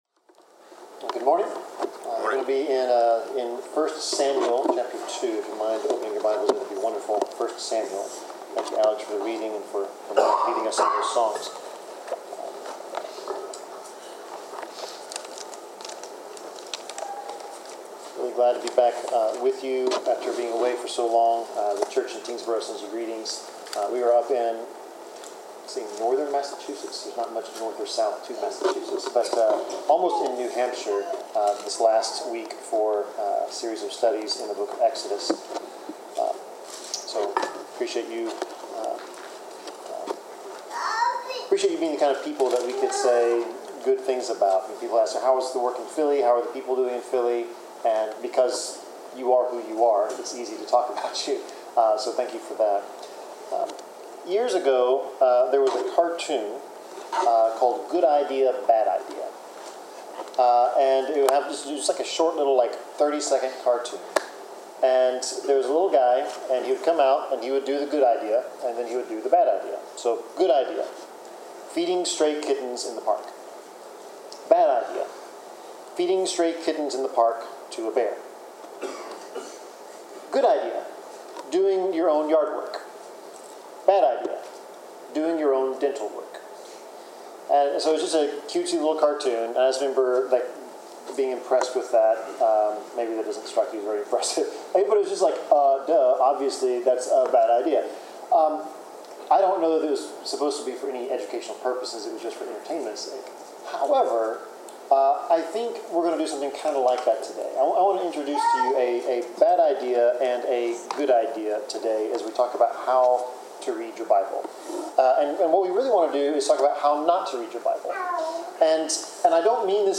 Passage: 1 Samuel 2:12-21 Service Type: Sermon